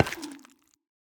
Minecraft Version Minecraft Version 25w18a Latest Release | Latest Snapshot 25w18a / assets / minecraft / sounds / block / sculk_catalyst / step1.ogg Compare With Compare With Latest Release | Latest Snapshot
step1.ogg